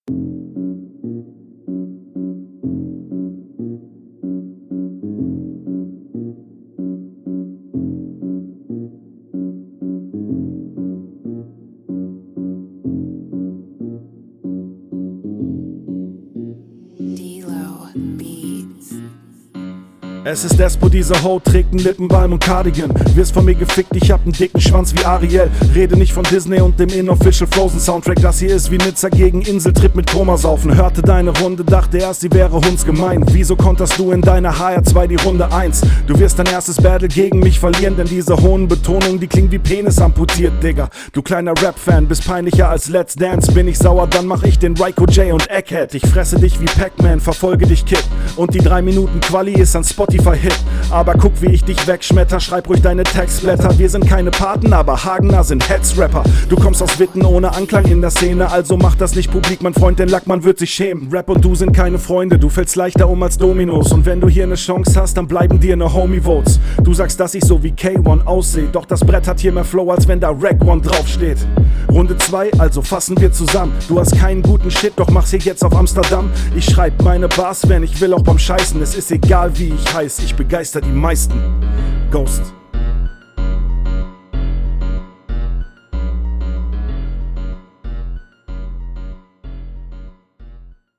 Stimme direkt angenehmer, Flow ruhiger, find ich gut.